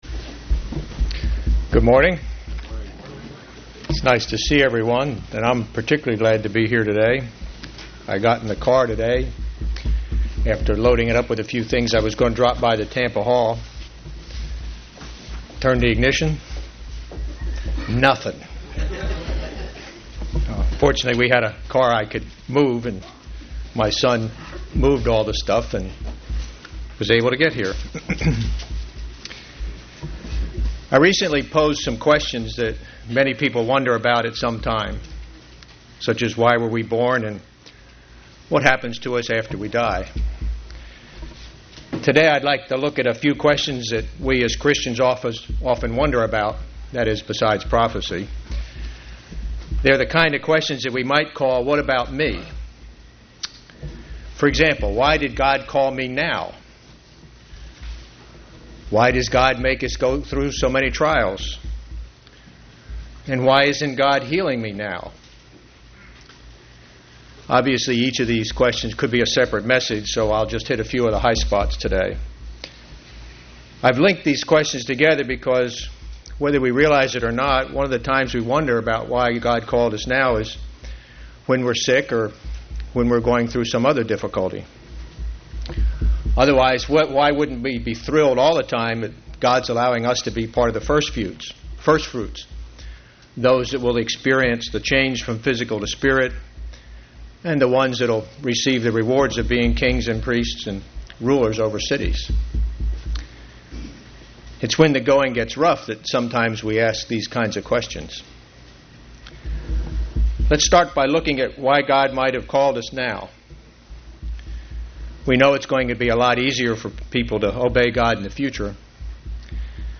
Given in St. Petersburg, FL
UCG Sermon